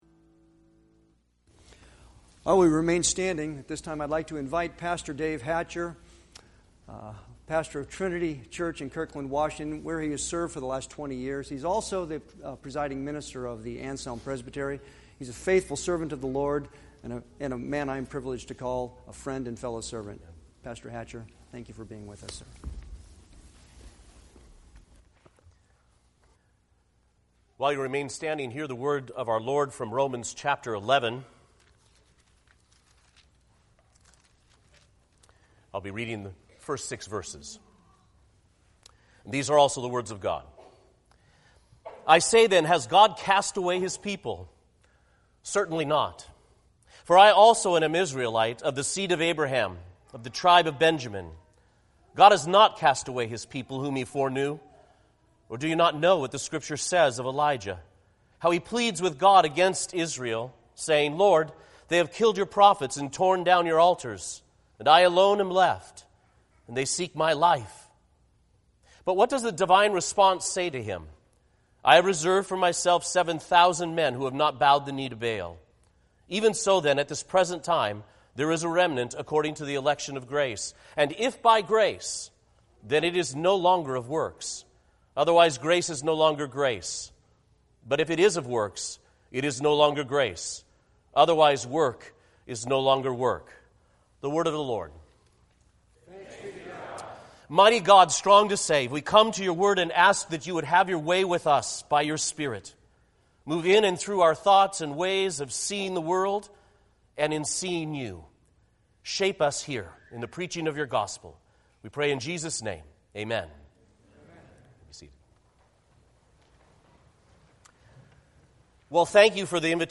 Sermons by visiting preachers
Service Type: Sunday worship